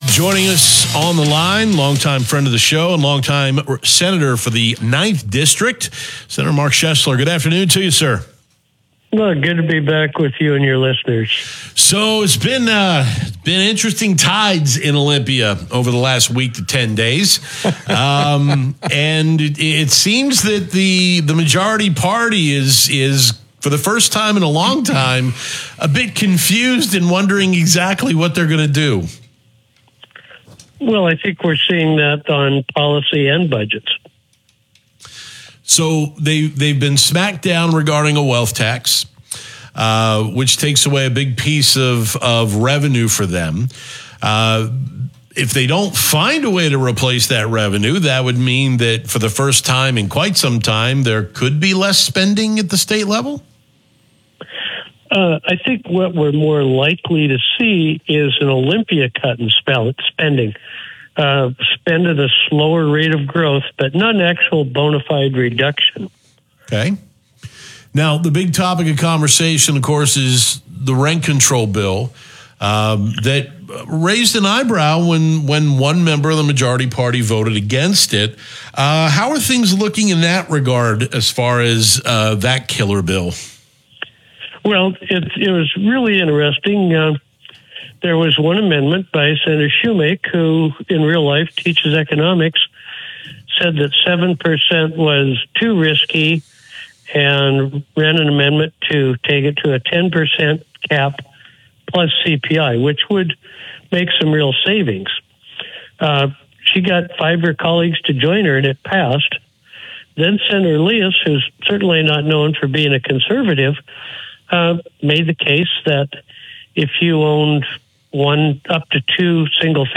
KONA Radio Interview: Sen. Mark Schoesler on Legislative Uncertainty & Policy Debates - Senate Republican Caucus